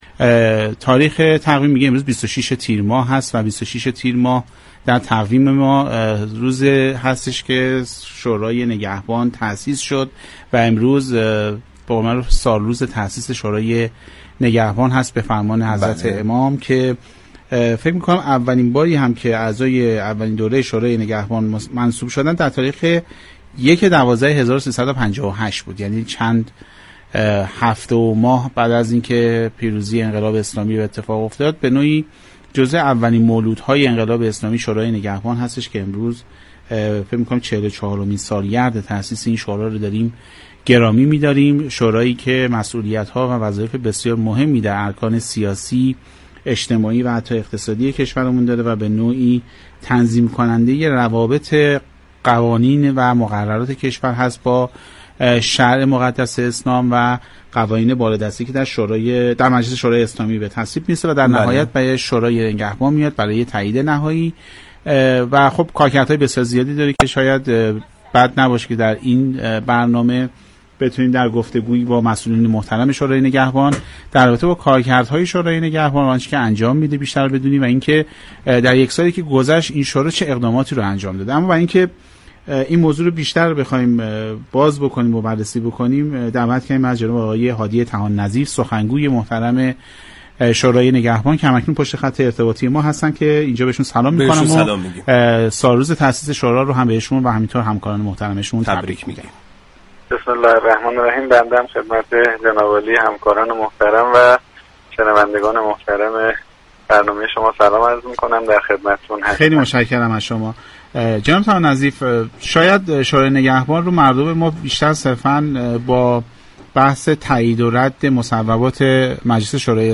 به گزارش پایگاه اطلاع رسانی رادیو تهران، هادی طحان نظیف سخنگوی شورای نگهبان به مناسبت 26 تیرماه سالروز تاسیس این نهاد به فرمان امام خمینی(ره) به تعدادی از پرسش‌های برنامه «سعادت آباد» درباره كاركردها و اقدامات پاسخ داد.